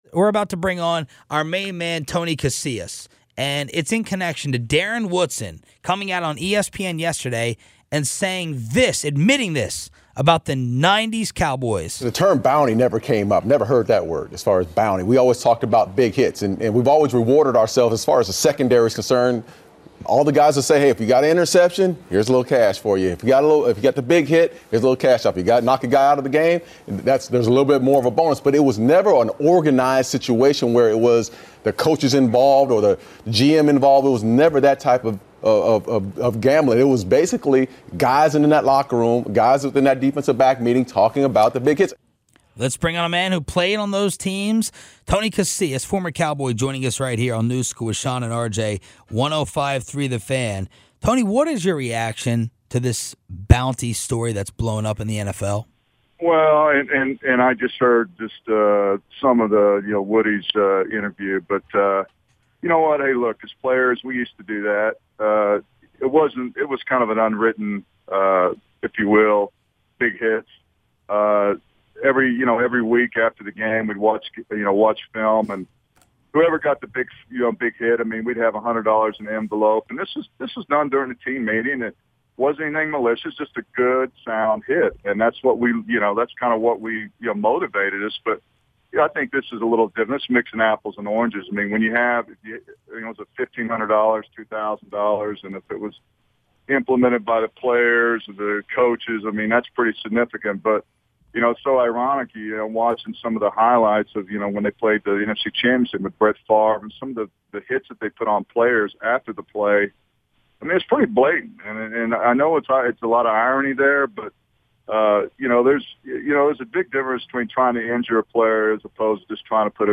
DALLAS (105.3 THE FAN) - In response to the bounty allegations against the New Orleans Saints, Former Cowboy Tony Casillas was on 105.3 The Fan and admitted that some of the Cowboys players had bounties during his days with the team (91-93, 96-97) and went into detail about what kind of cash was on the table.